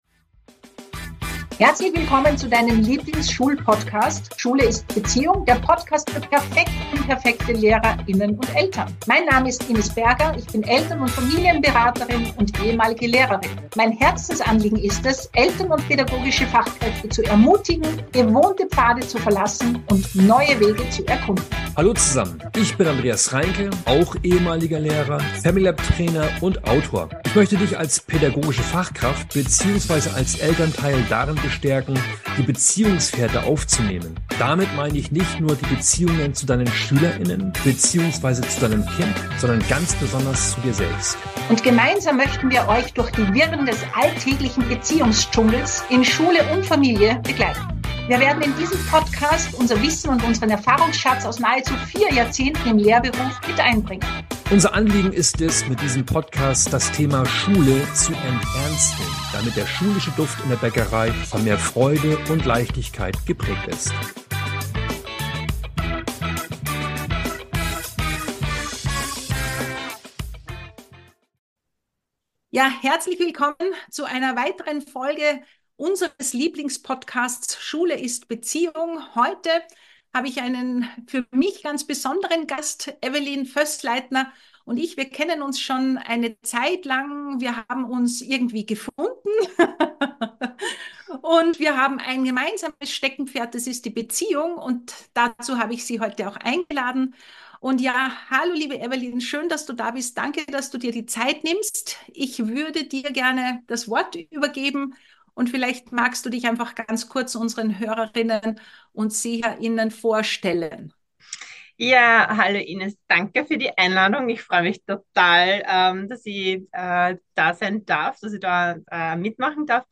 Sie sprechen über die Notwendigkeit, veraltete Lehrmethoden zu hinterfragen und wie man durch beziehungsorientierte Ansätze eine unterstützende und verständnisvolle Lernumgebung schafft. Dieser humorvolle Austausch bietet wieder tiefe Einblicke ins "Unterrichtsgeschehen" und ist eine wertvolle Ressource für alle, die ihre pädagogische Praxis bereichern möchten.